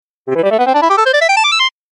Откройте для себя коллекцию звуков нового уровня — инновационные аудиоэффекты, футуристические мелодии и необычные композиции.